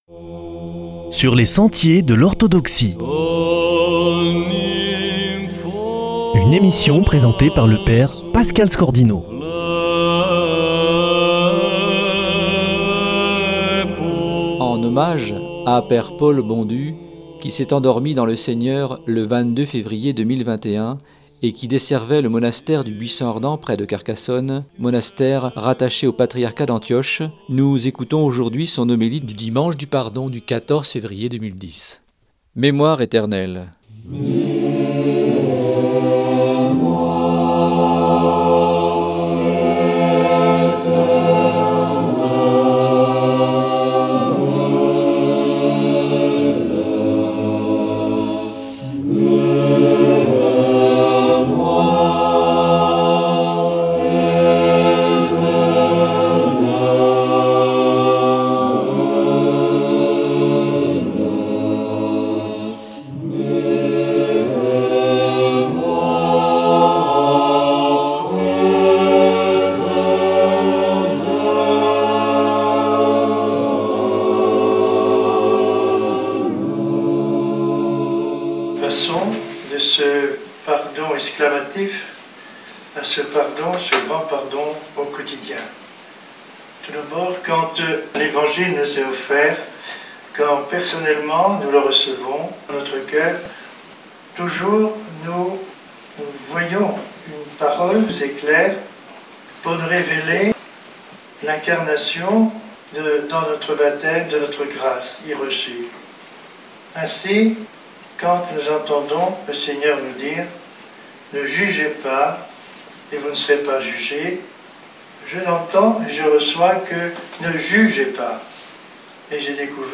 homélie 2010 du dimanche du pardon (14/02/2010)